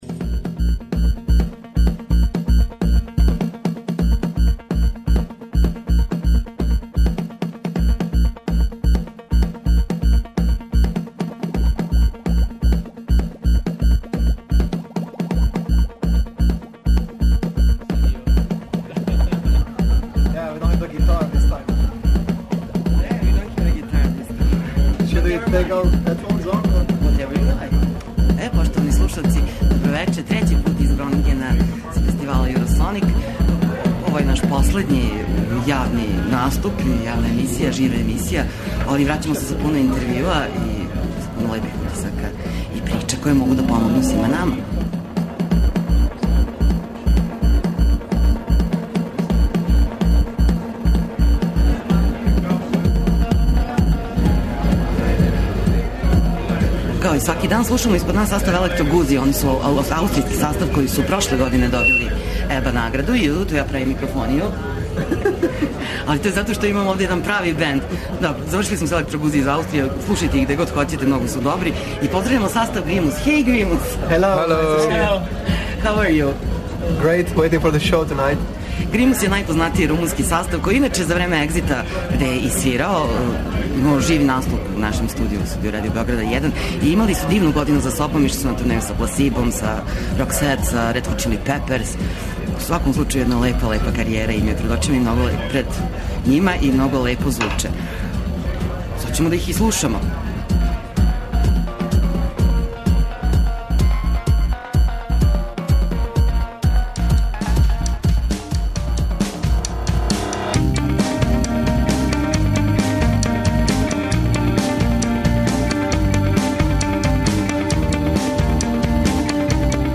Екипа Радио Београда 1 и ове године реализује директне преносе са Eurosonic фестивала у Холандији.